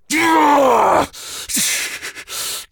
B_pain4.ogg